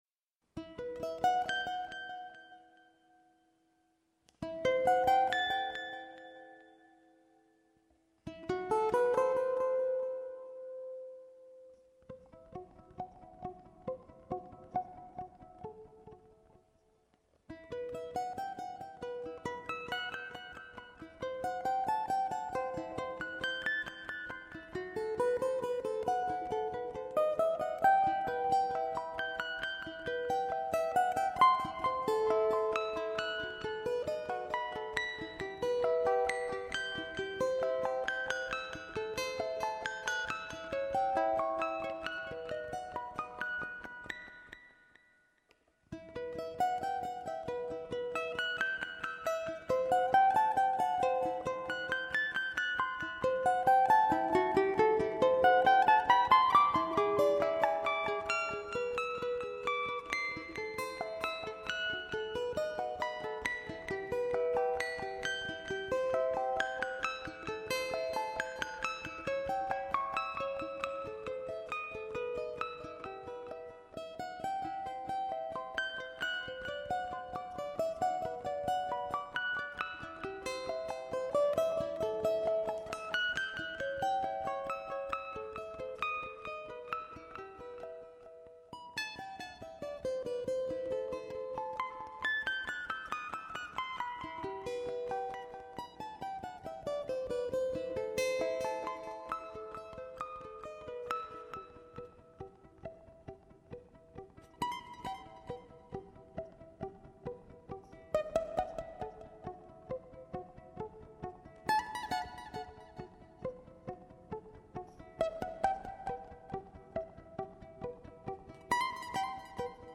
🎵 موسیقی متن